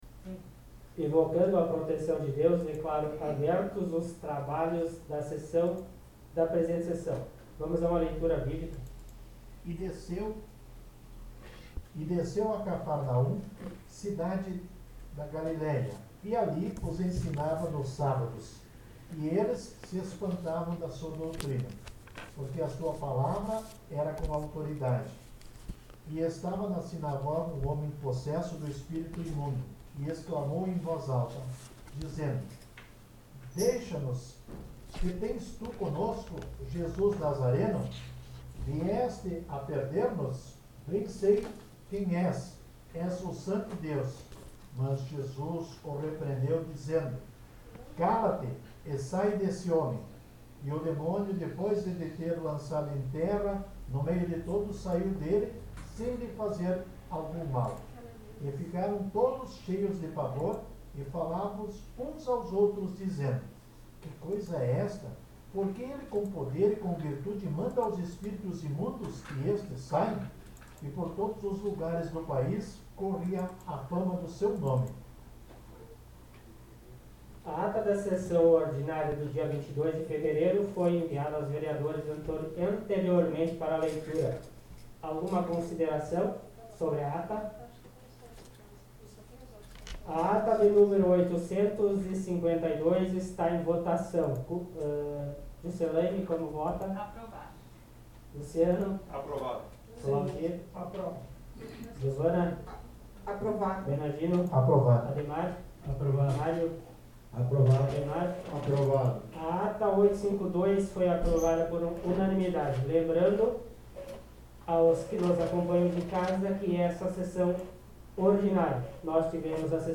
Sessão Ordinária do dia 08 de março de 2021